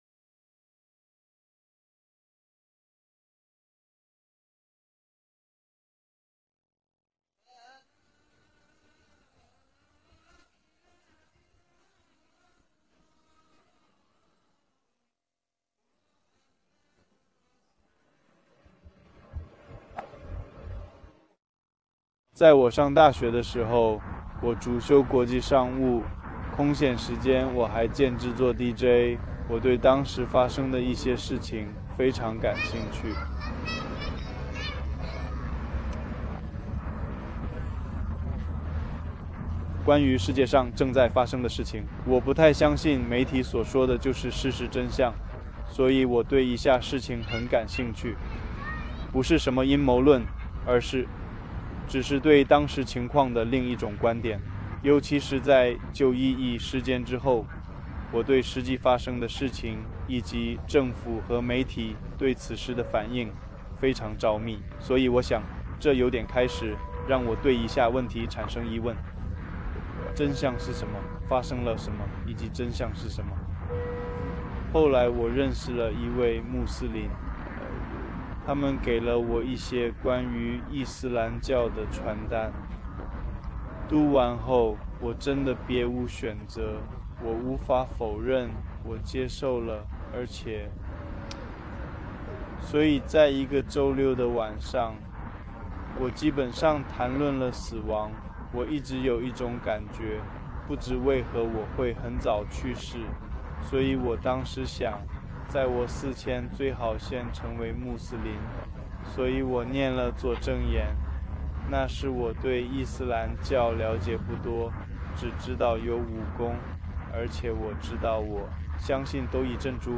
视频 新穆斯林故事 男性